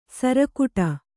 ♪ sarakuṭa